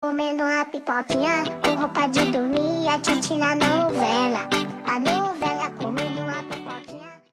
comendo uma pipoquinha com roupa de dormir Meme Sound Effect
Category: Meme Soundboard